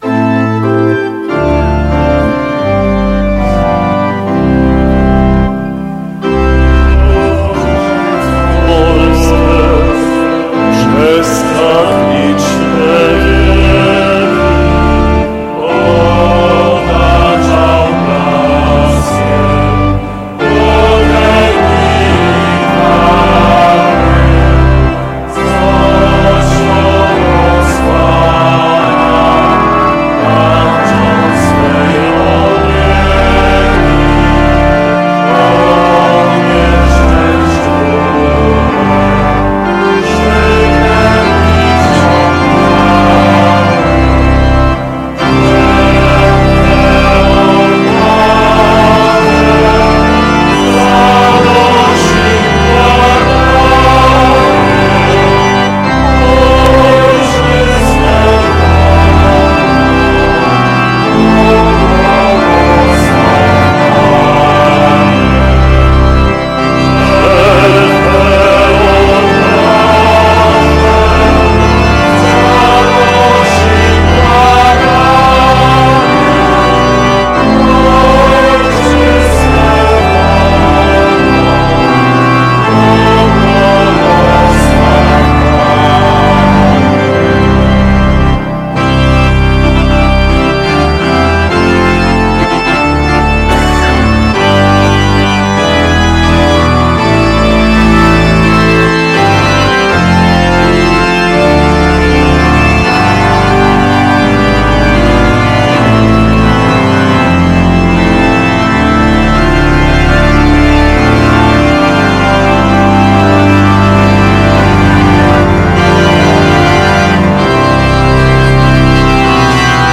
Msza Święta o godzinie 19.18 w Świątyni Opatrzności Bożej
Również i ta Msza św. zakończyła się odśpiewaniem pieśni Boże coś Polskę.